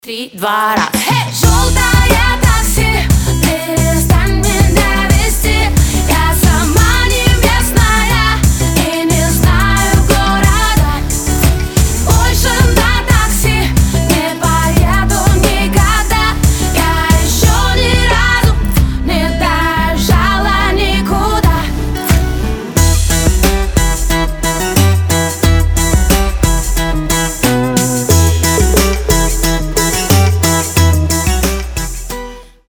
женский вокал
Хип-хоп
заводные